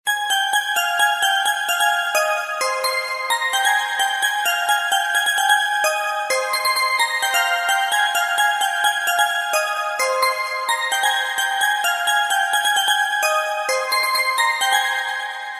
Kategorien: Wecktöne